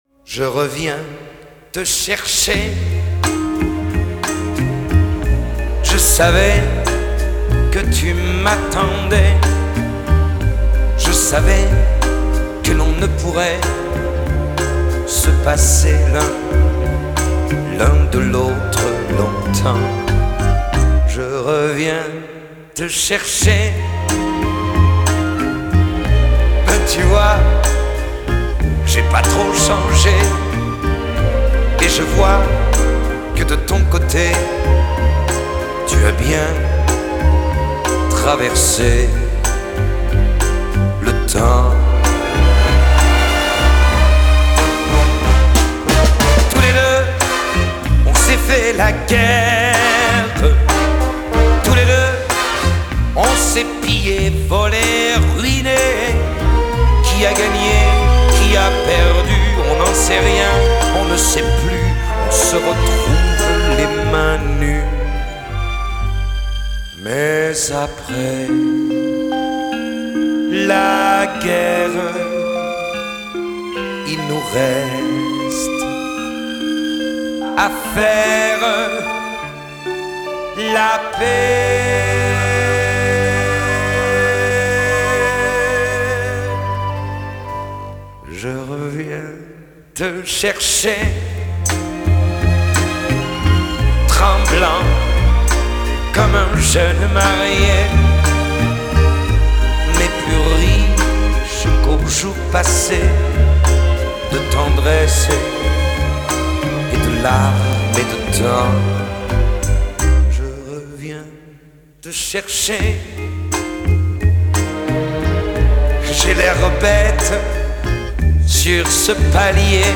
лирическая песня
музыка к кино